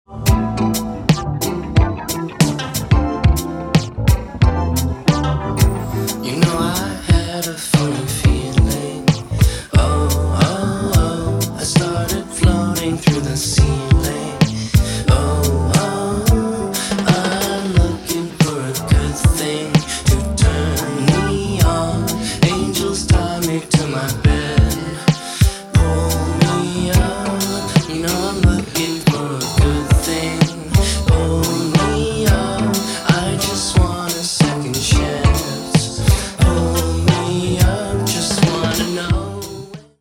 全編に渡って脱力感の漂うベッドルーム・ポップ/シンセ・ファンク/ダウンテンポetcを展開しています。